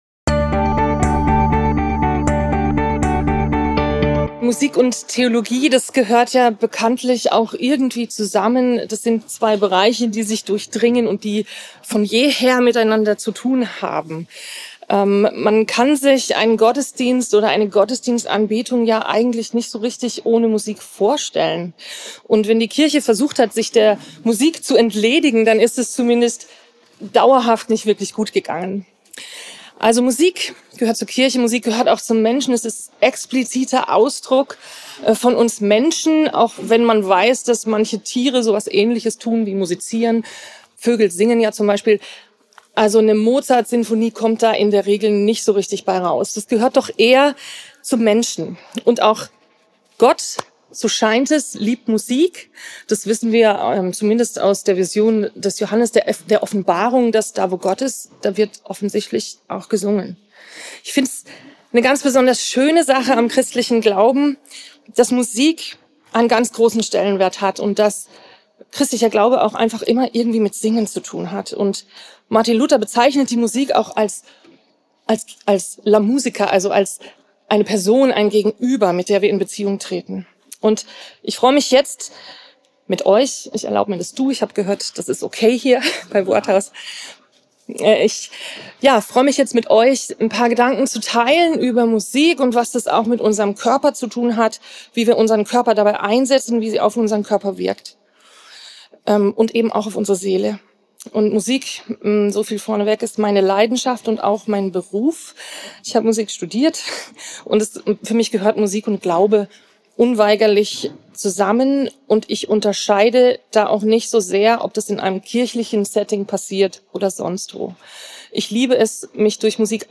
Worthaus Sommercamp 2023 – Volkenroda: 7.